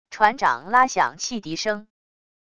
船长拉响汽笛声wav音频